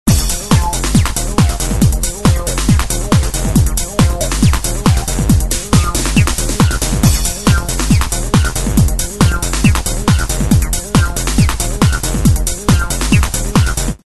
ReBirth propose deux TB-303, une TR-808 et une TR-909, le tout sur un seul et même écran, pilotable entièrement à la souris. On a également droit à un filtre et une distorsion.